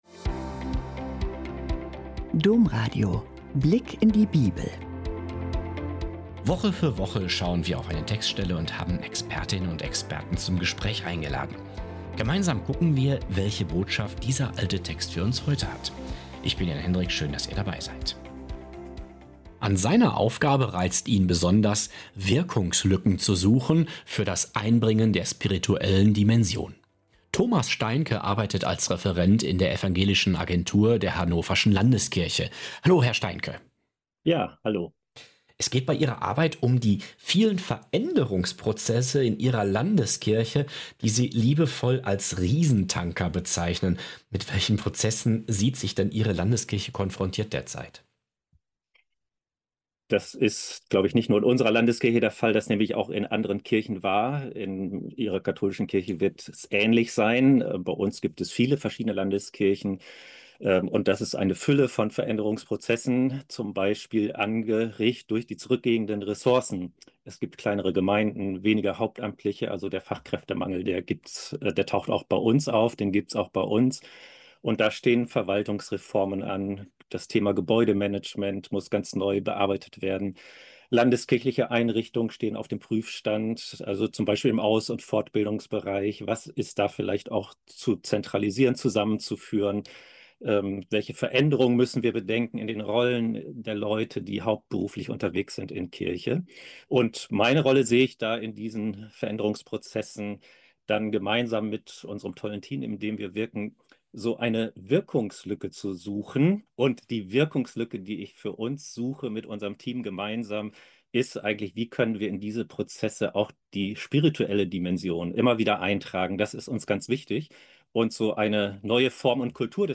Ein Gespräch, das Mut macht, Glauben und Verantwortung neu zusammenzudenken – und unbedingt Lust aufs Weiterhören weckt.